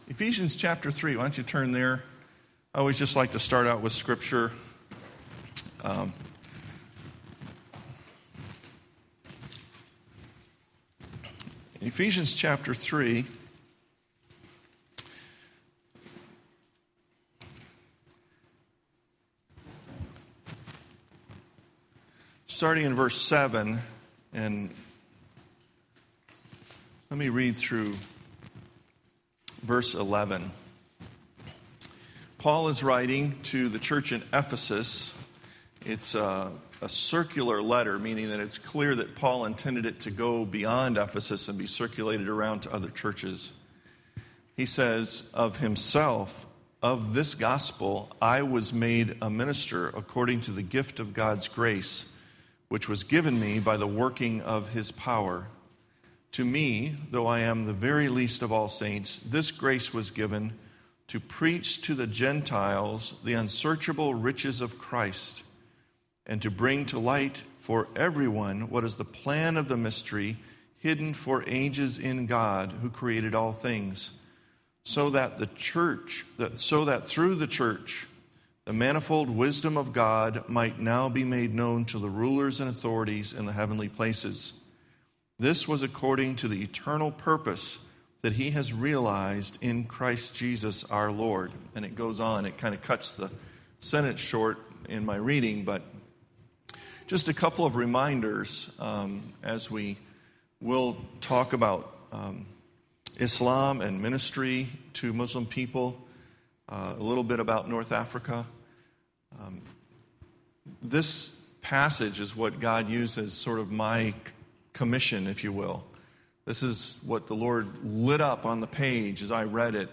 Play the sermon Download Audio ( 29.15 MB ) Email Questions and Answers about Islam Details Series: General Topics Date: 2011-06-12 Scripture : Selected Scriptures